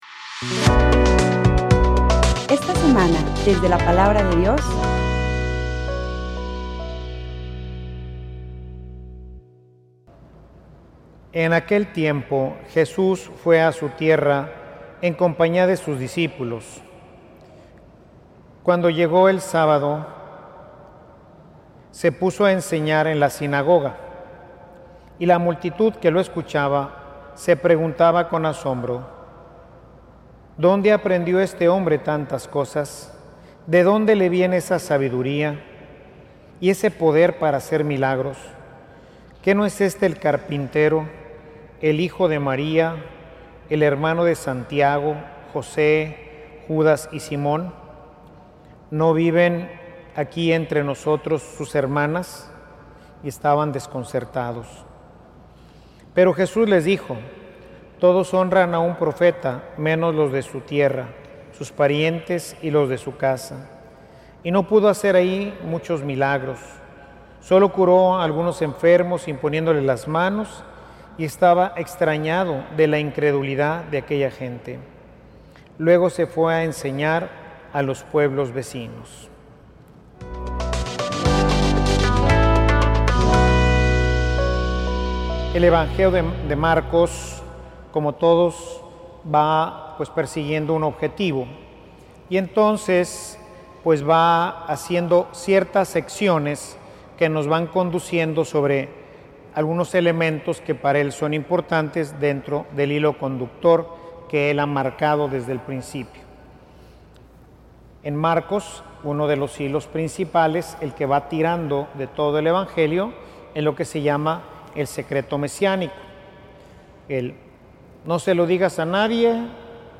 Homilia_Ni_viendo_creen.mp3